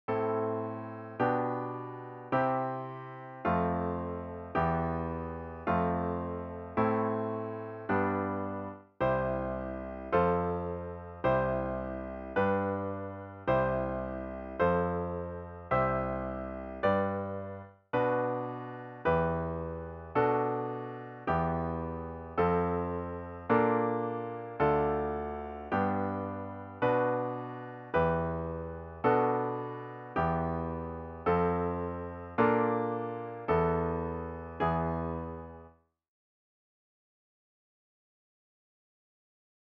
The examples are in C major/A minor, but are of course transposable to any key.
A new browser window will open, and you’ll hear each progression in basic long-tones.
Listen VERSE:  Am  G/B  C  F  Em  F  Am  G  || CHORUS:  C  F  C  G  C  F  C  G  || BRIDGE:  Bm  Em Bm7  Em  F  Bbmaj7  Dm  G  Bm  Em  Bm7  Em  F  Bbmaj7  Esus4  E